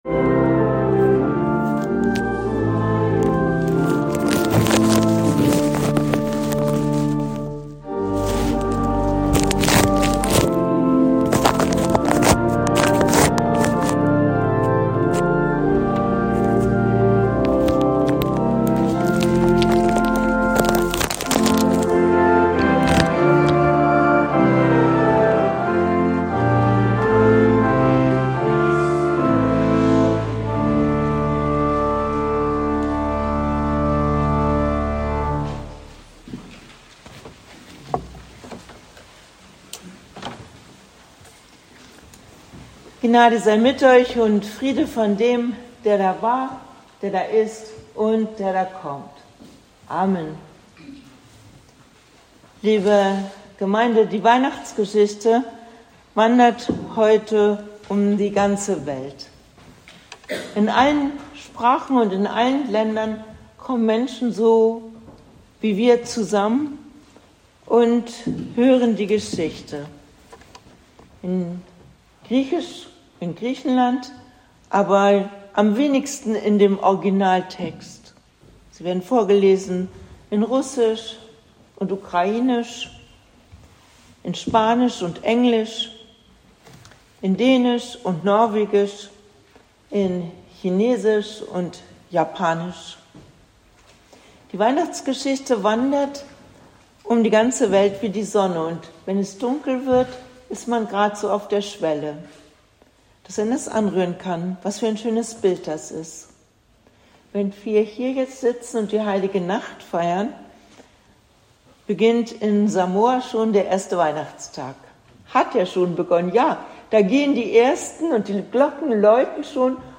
Predigt
am Heiligen Abend 2025, Christmette